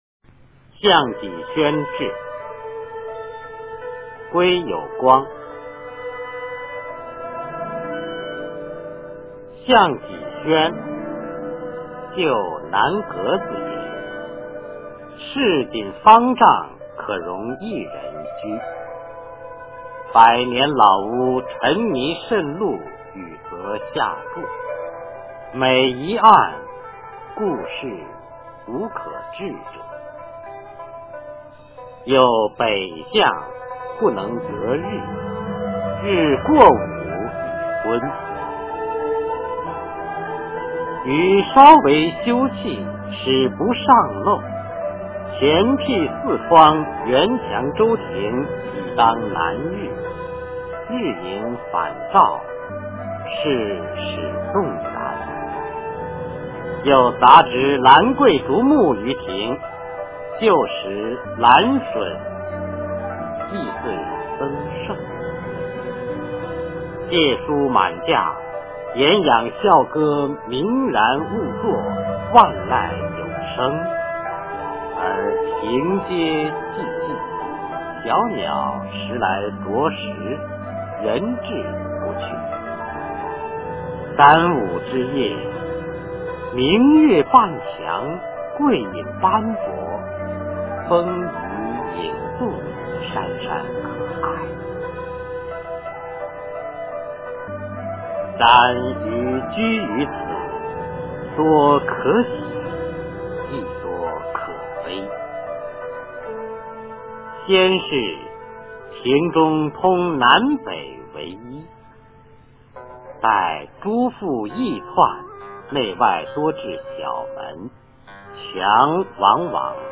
《项脊轩志》原文和译文（含鉴赏、朗读）